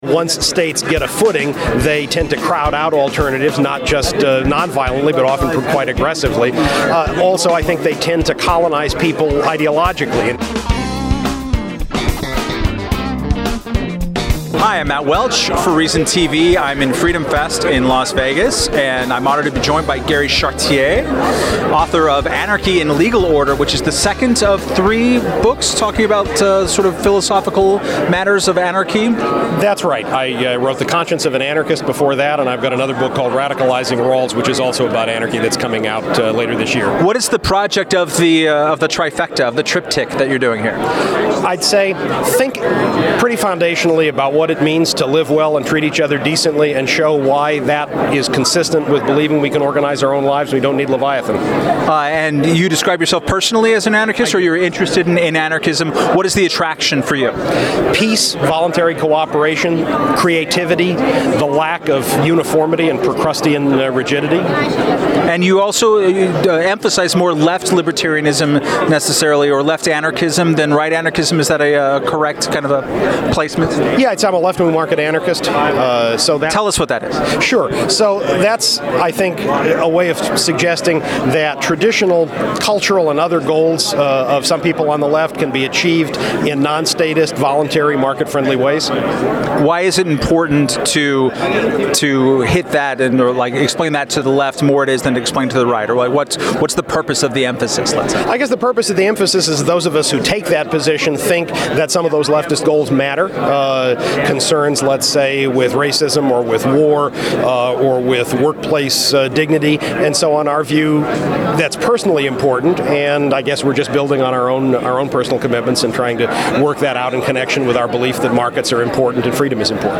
Held each July in Las Vegas, Freedom Fest is attended by around 2,000 limited-government enthusiasts and libertarians. ReasonTV spoke with over two dozen speakers and attendees and will be releasing interviews over the coming weeks.